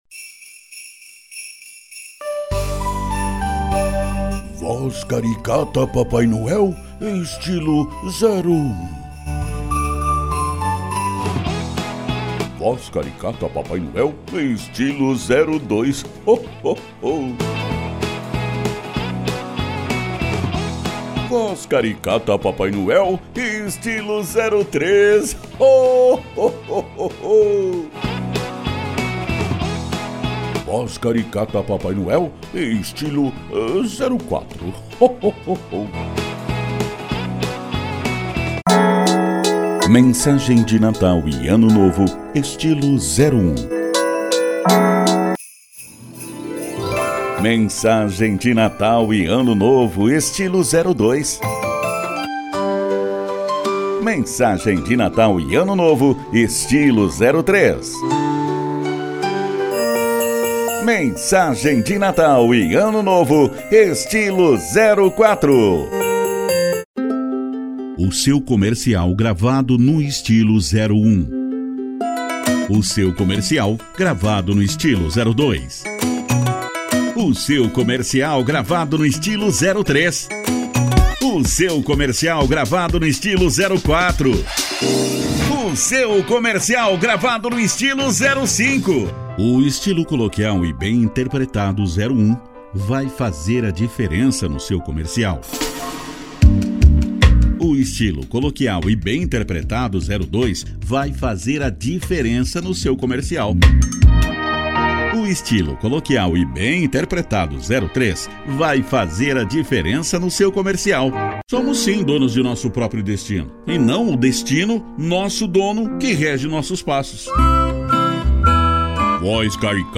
Estilo(s):
Padrão
Impacto
Animada
Caricata